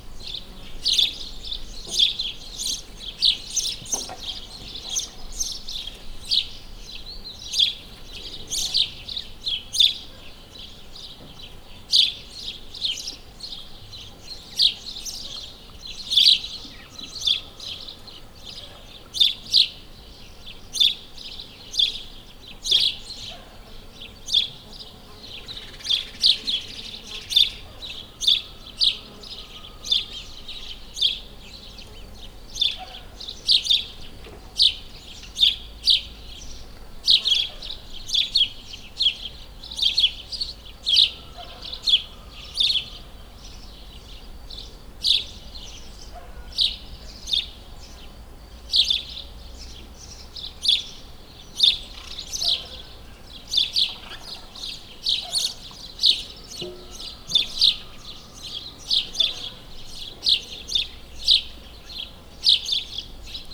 allathangok > termeszetben > hazivereb_professzionalis2014
juniuskozepe2_szolosardo_sds01.03.WAV